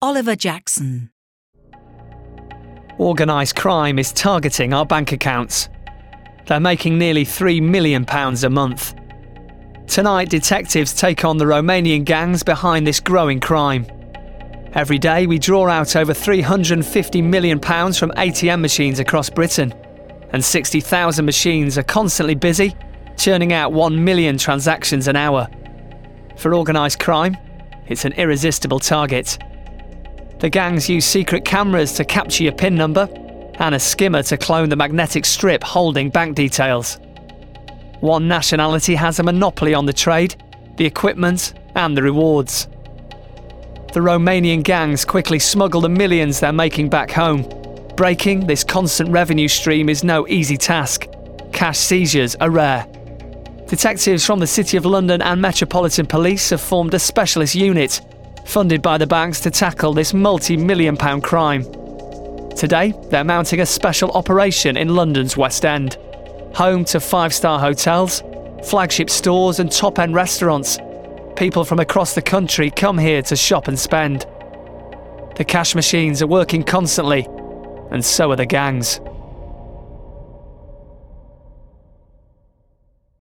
Description: Northern: clear, bright, direct
Age range: 30s - 40s
Commercial 0:00 / 0:00
Glasgow, Lancashire, Northern*, RP, Yorkshire